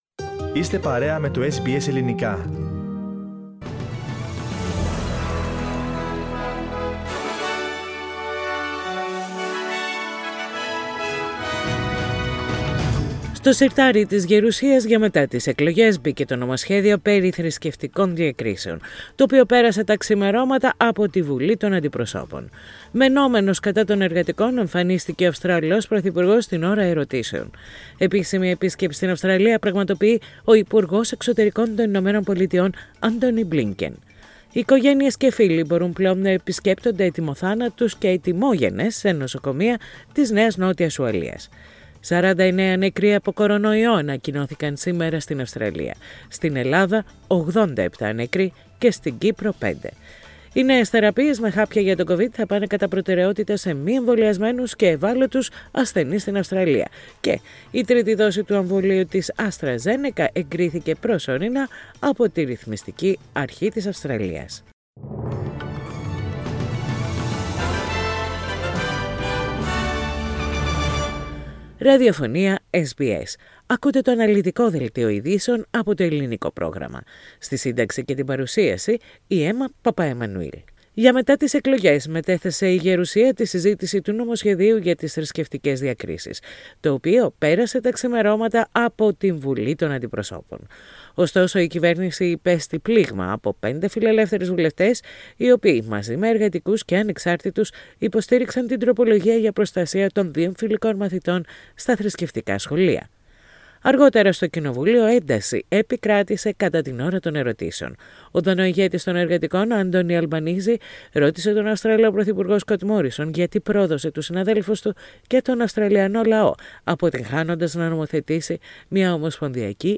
The detailed bulletin with the main news of the day from Australia, Greece, Cyprus and the rest of the world.